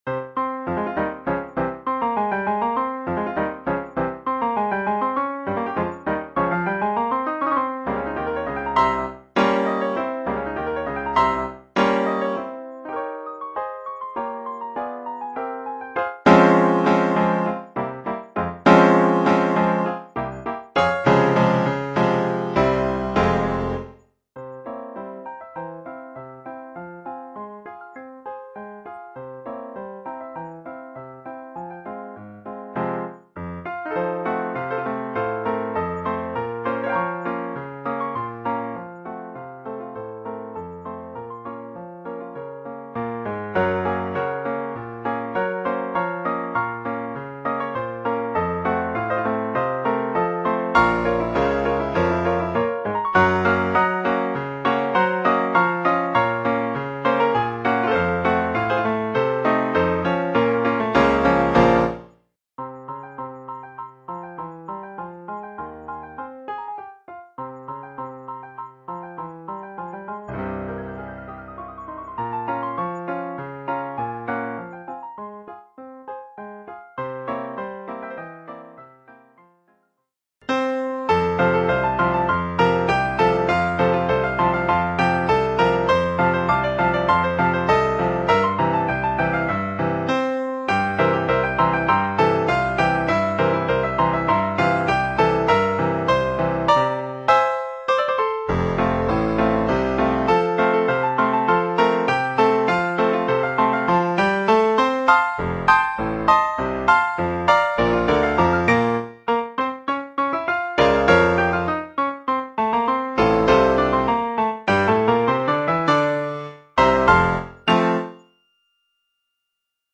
Collection : Piano
Transcription pour piano solo, par